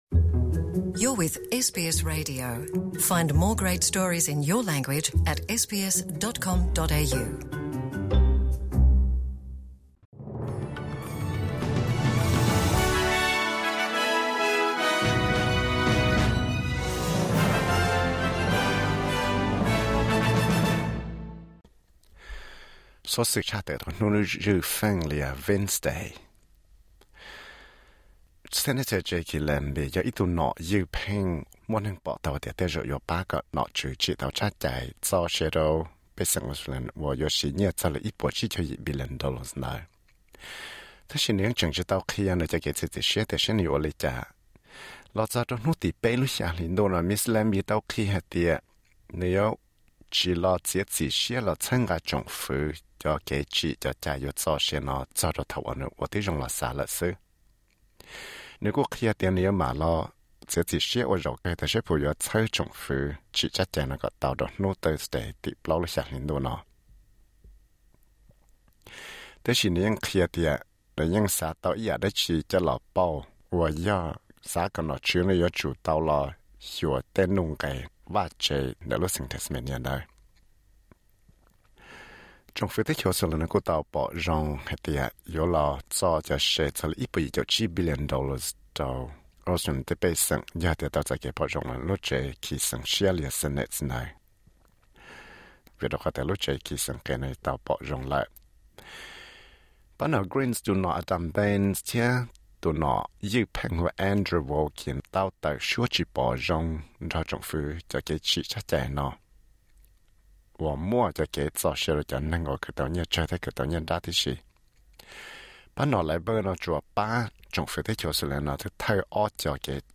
Xov xwm tshaj tawm rau hnub zwj Feej (Wednesday) zwj thaj tim 03.07.2019.